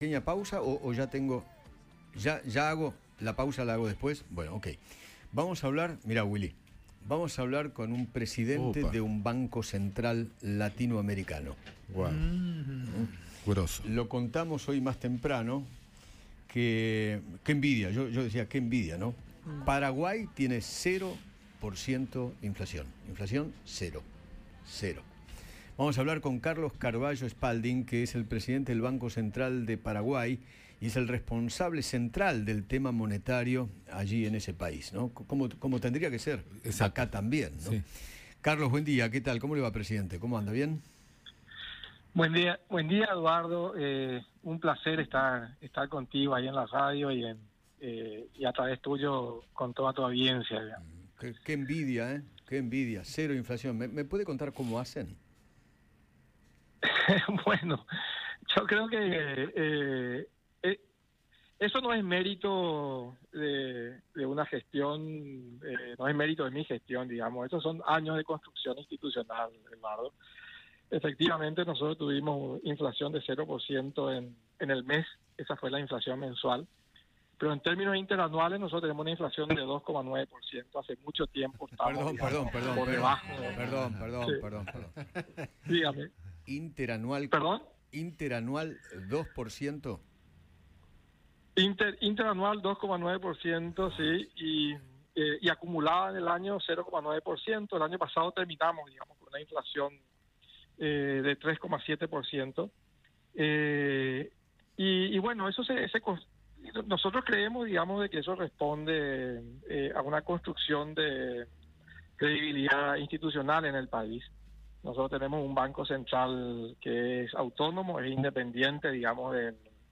Carlos Carvallo Spalding, presidente del Banco Central de Paraguay, conversó con Eduardo Feinmann sobre las medidas económicas en ese país y describió cómo llegaron a tener “cero inflación”.